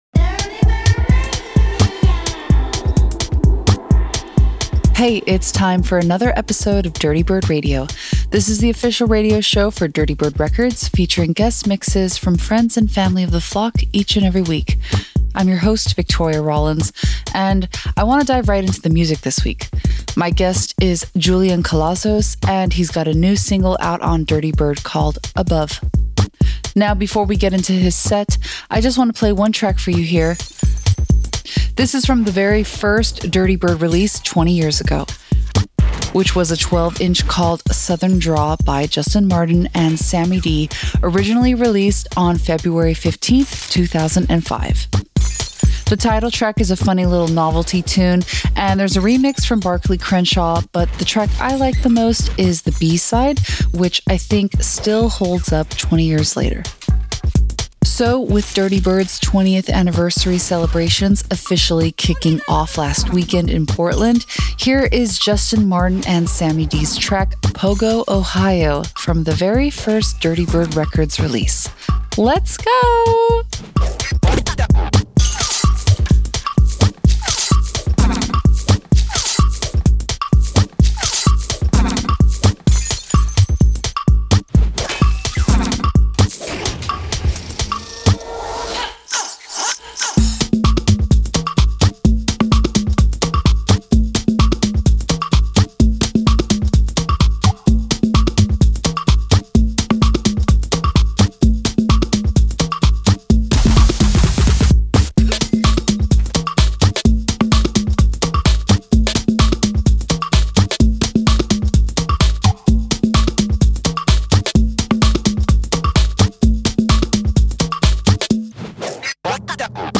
alternative bass music to funk based house and electronica